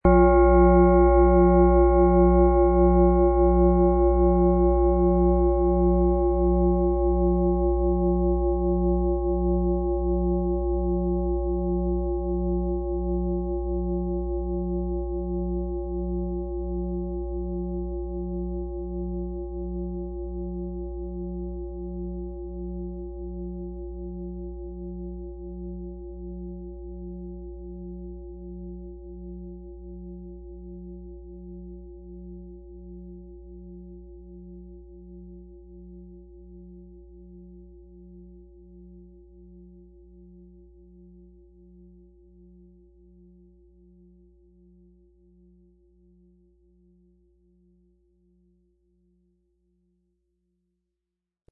Planetenton 1
Wasser
XXL Planeten-Fußreflexzonen-Klangschale mit Wasser und Mond-Ton – Loslassen & innere Balance
Mit einem Gewicht von ca. 8,8 kg entfaltet sie eine kraftvolle, lang anhaltende Schwingung, die deinen gesamten Körper sanft durchströmt.
Im Sound-Player - Jetzt reinhören hören Sie den Original-Ton dieser Schale.